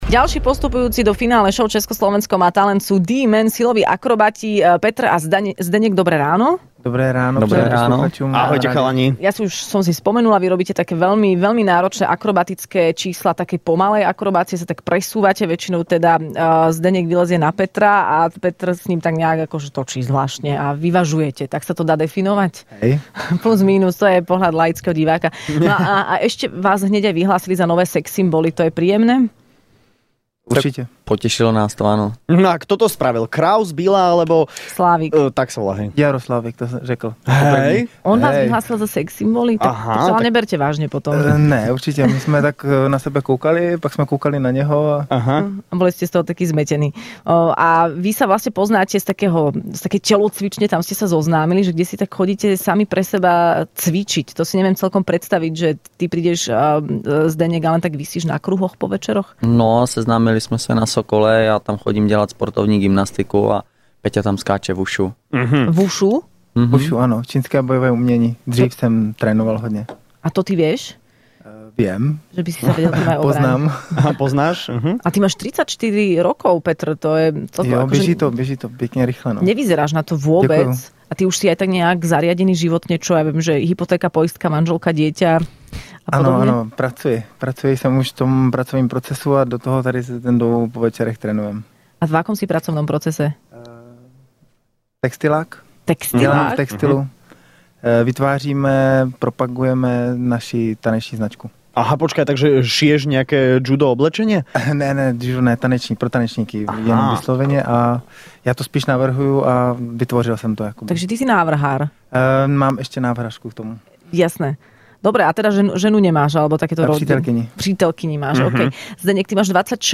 V Rannej šou boli hosťami finalisti šou Česko - Slovensko má talent ale okrem nich prišla aj Zuzka Smatanová...